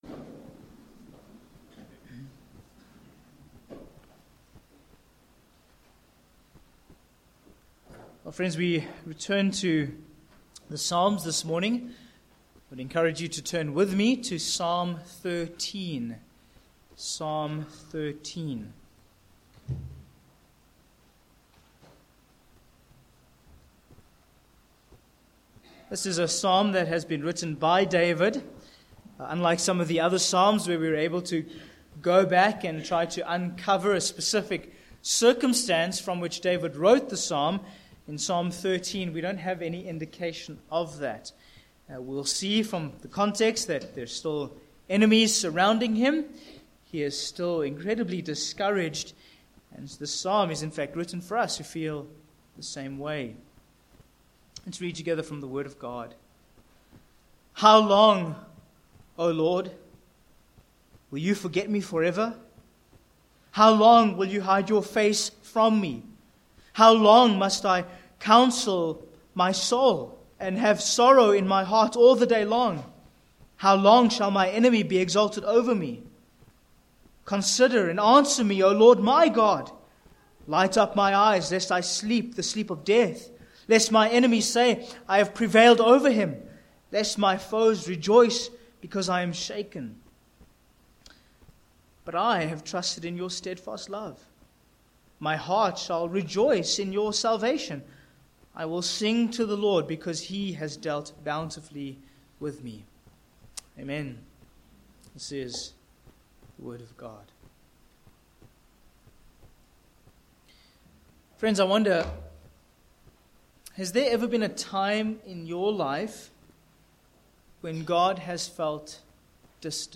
Sermon points: 1. A Cry of Affliction v1-2, 4